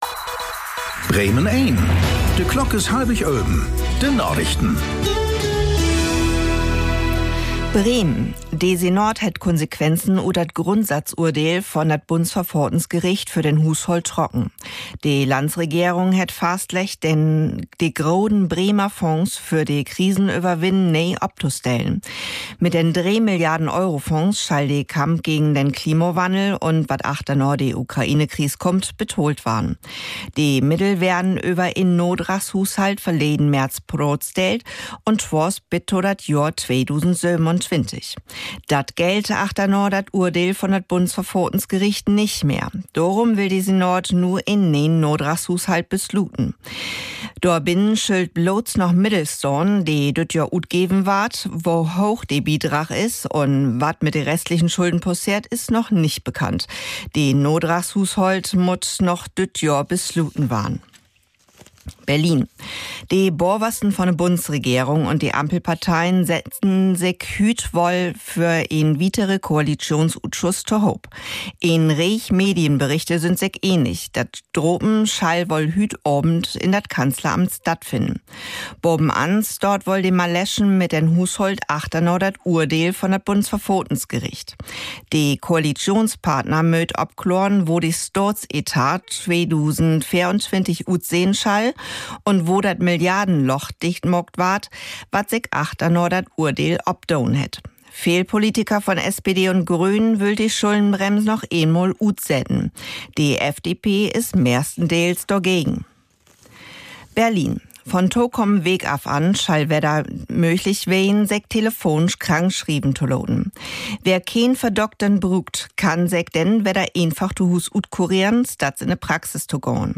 Aktuelle plattdeutsche Nachrichten werktags auf Bremen Eins und hier für Sie zum Nachhören.
… continue reading 1110 episódios # Tägliche Nachrichten # Nachrichten # Thu Apr 01 11:24:10 CEST 2021 Radio Bremen # Radio Bremen